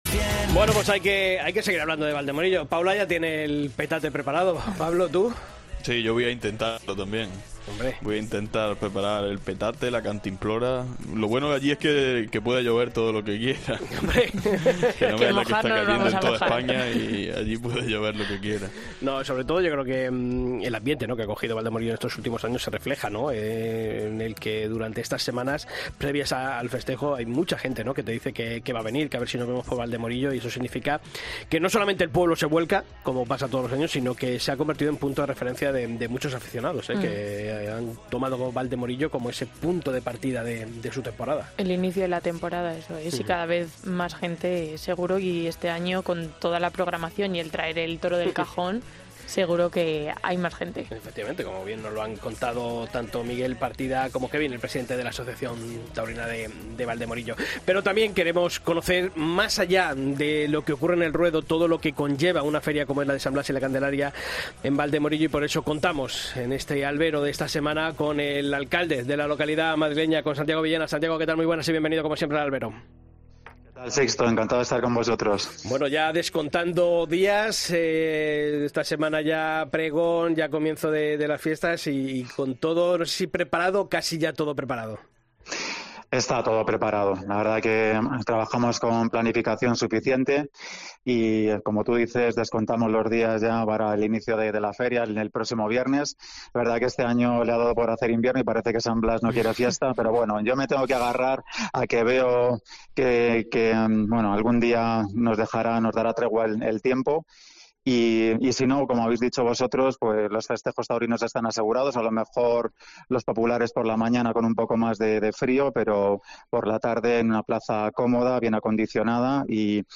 El alcalde de Valdemorillo pasa por El Albero para explicar la apuesta por la Tauromaquia que lleva a cabo el ayuntamiento de la localidad madrileña.